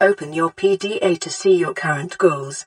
OpenPDA.wav